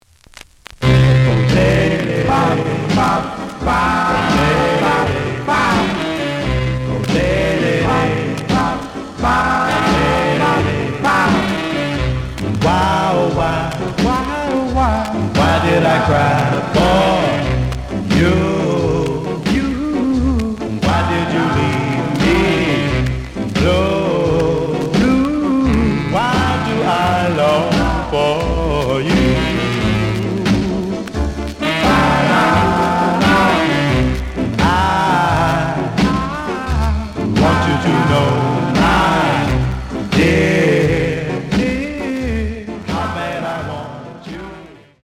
The audio sample is recorded from the actual item.
●Genre: Rhythm And Blues / Rock 'n' Roll
Looks good, but some periodic noise on first half of A side.